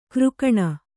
♪ křkaṇa